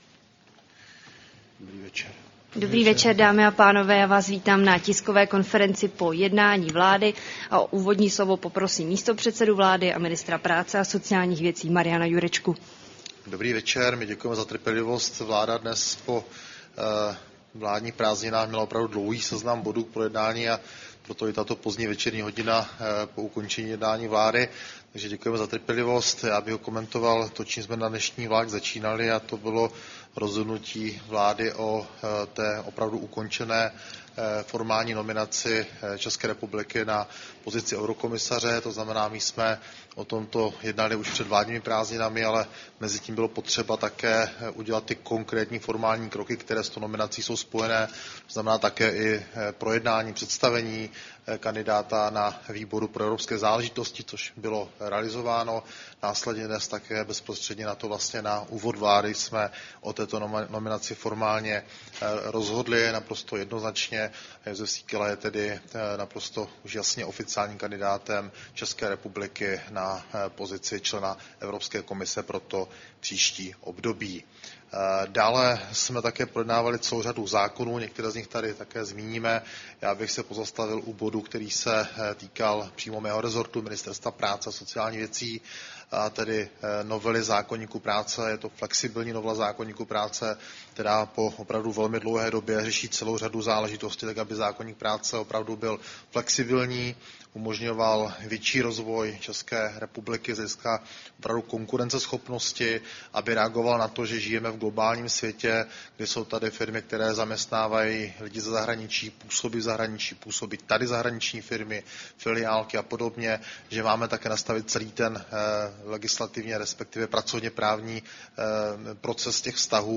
Tisková konference po jednání vlády, 21. srpna 2024